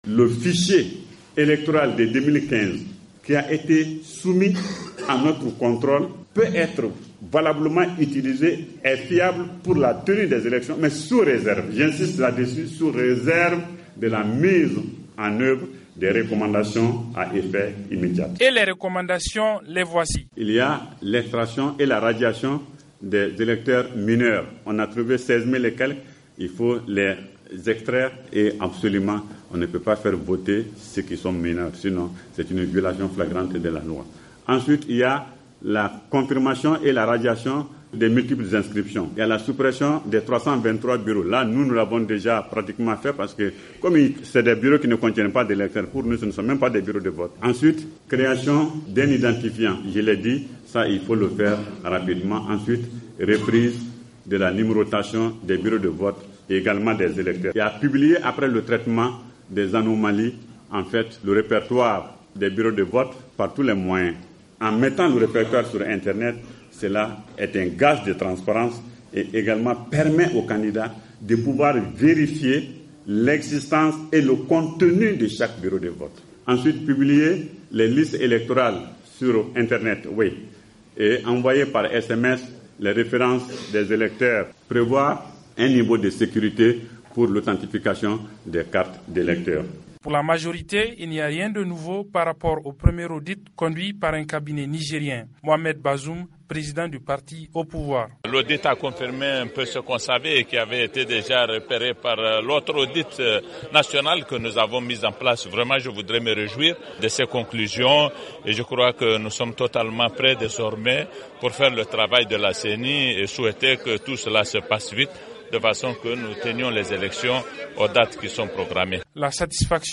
à Niamey